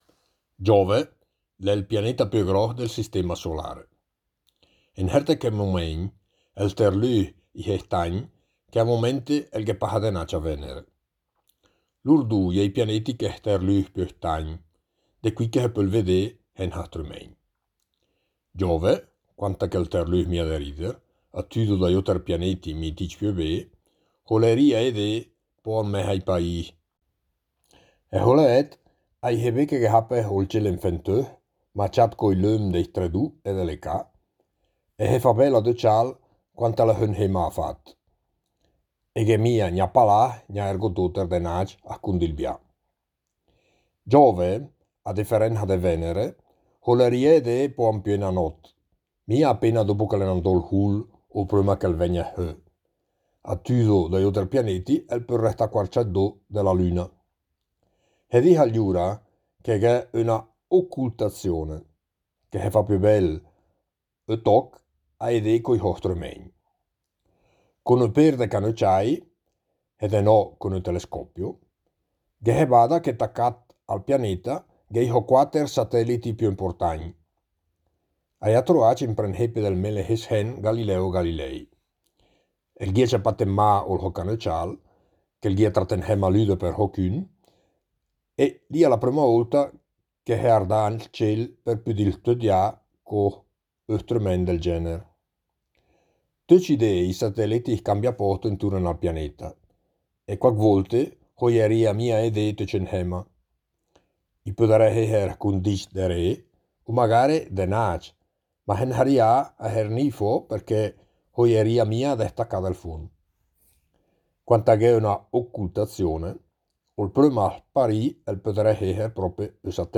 13 – Giove (dialetto lumezzanese)
Queste parole sono scritte e lette ad alta voce in lingua ladina e in dialetto lumezzanese, ma i promotori del progetto confidano che altri territori organizzino analoghe attività in diverse parlate locali.
13-Giove-dialetto-lumezzanese.mp3